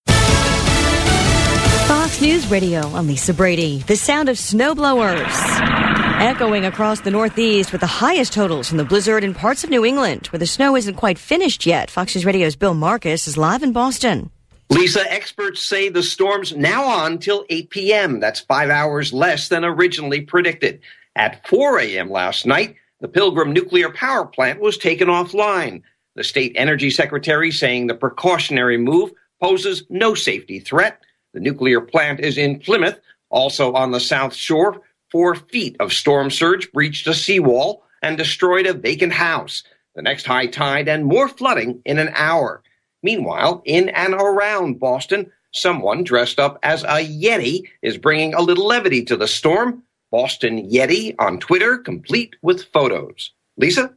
3PM LIVE